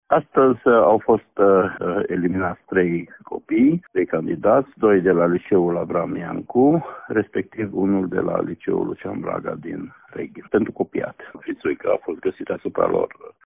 Trei dintre ei au ratat bacalaureatul pentru că au fost prinşi cu fiţuici, a explicat Inspectorul Școlar General, Ştefan Someşan: